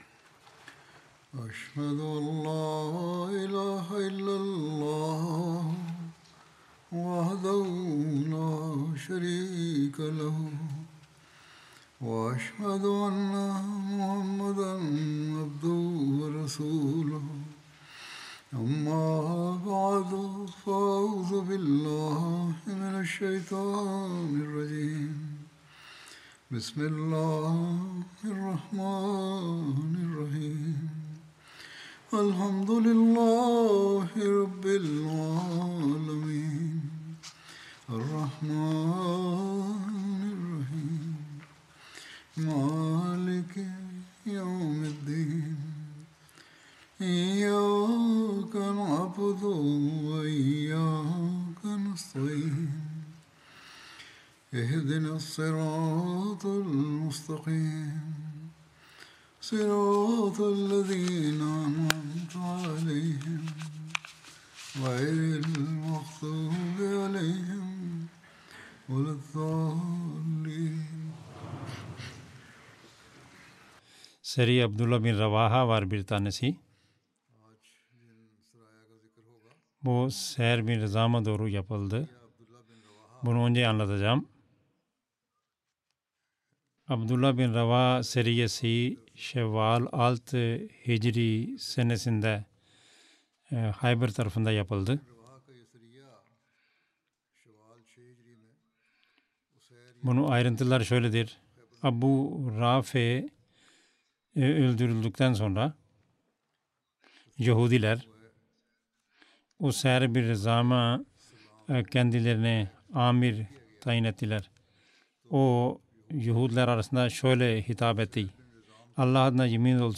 Turkish Friday Sermon by Head of Ahmadiyya Muslim Community
Turkish Translation of Friday Sermon delivered by Khalifatul Masih